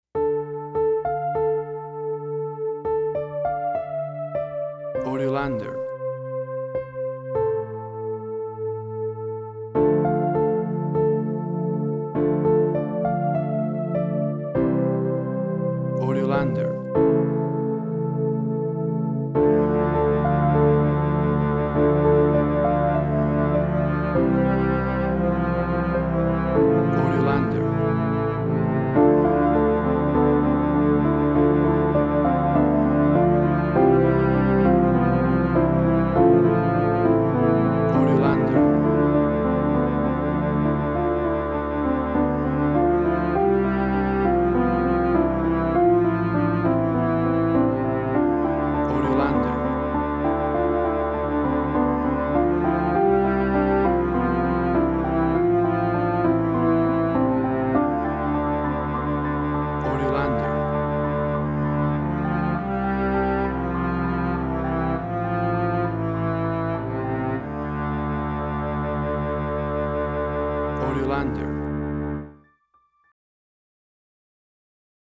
Indie Quirky.
Tempo (BPM): 100